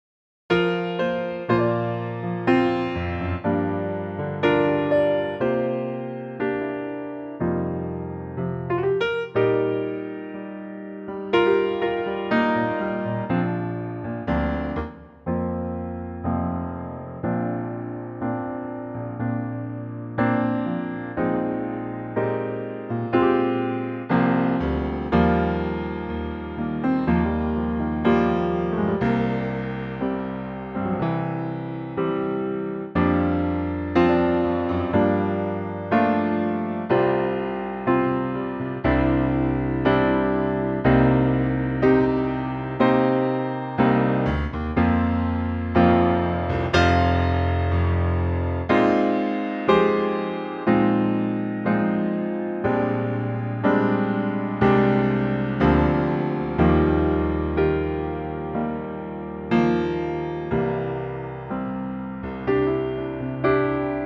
Unique Backing Tracks
key - Bb - vocal range - D to F (optional G)